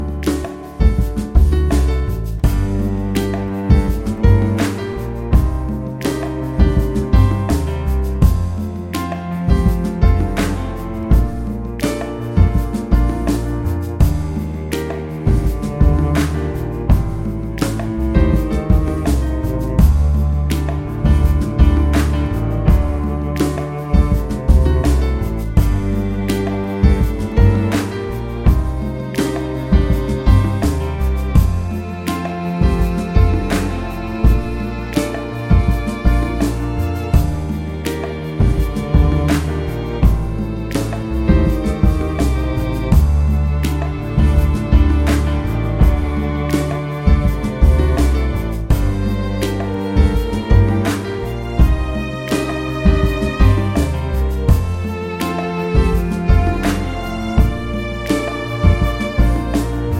Up 3 Semitones For Female